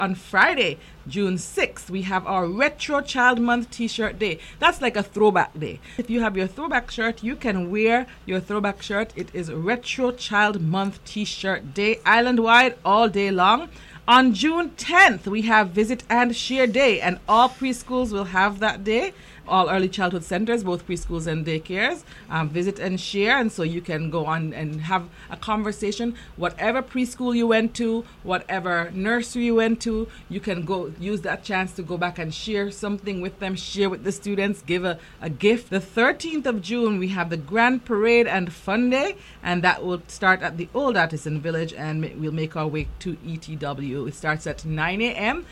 During Saturday’s edition of Youth on the Go, Permanent Secretary in the Ministry of Education, Ms. Zahnela Claxton provided a rundown of the celebratory events: